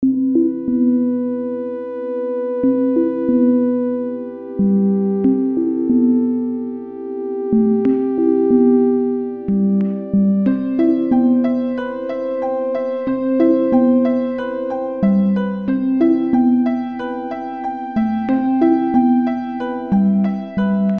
迷幻的合成器垫
描述：迷幻的合成器垫 享受
Tag: 140 bpm Hip Hop Loops Pad Loops 2.31 MB wav Key : Unknown